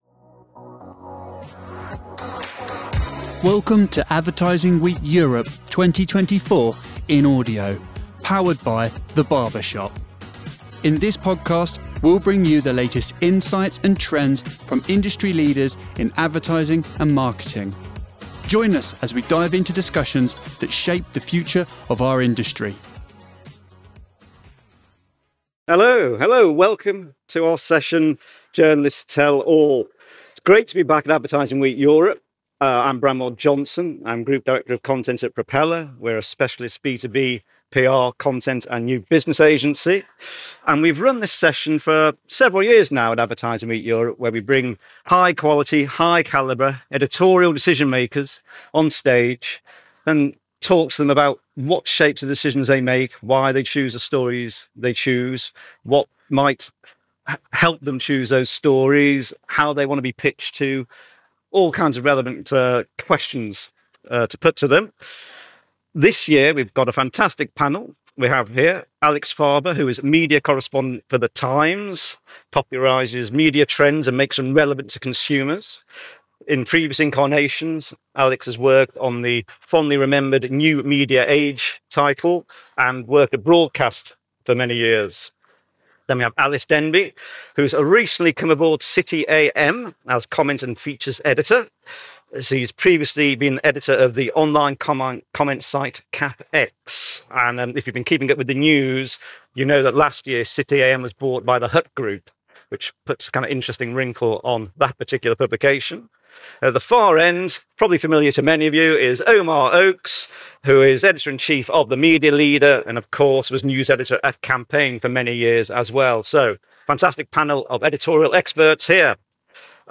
Advertising Week Europe 2024 in Audio